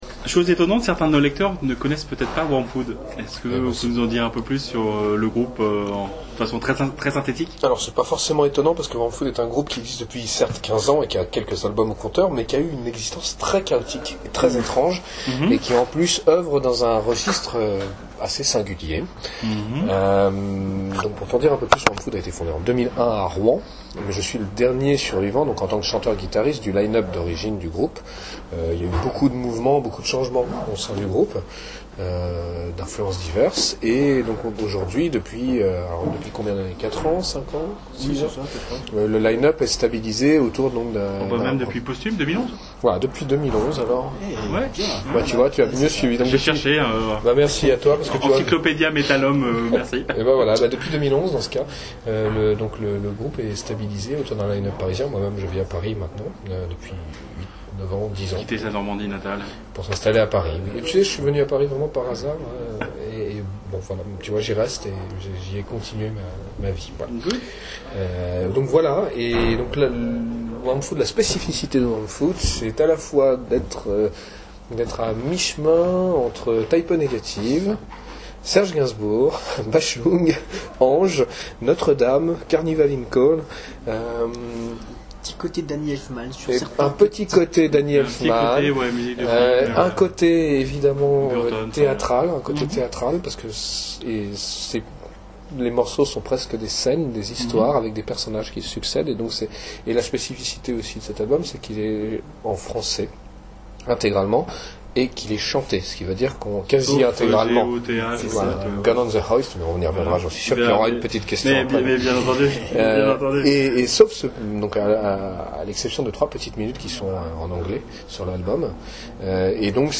WORMFOOD (Interview